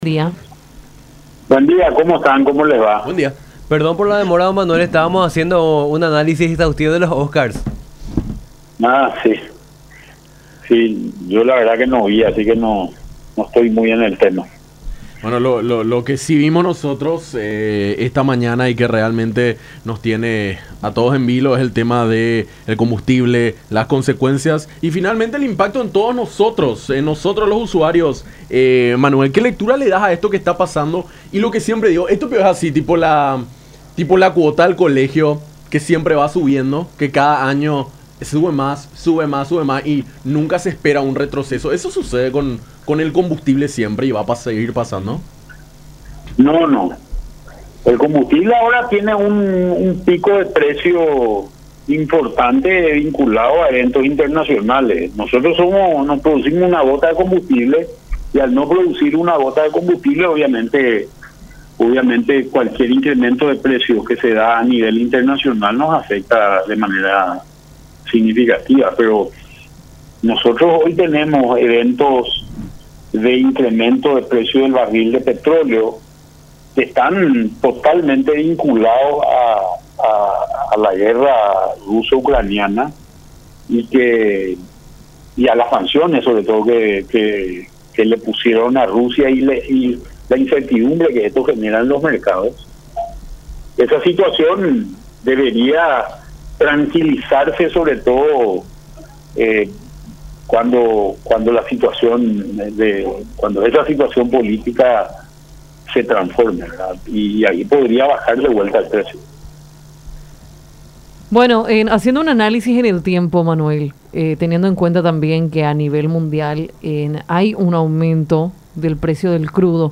Manuel Ferreira, economista y exministro de Hacienda.
A mí me parece que lo que se debe evitar es que haya subas tan pronunciadas que generen incertidumbre en la gente”, expuso Ferreira en contacto con Nuestra Mañana por La Unión.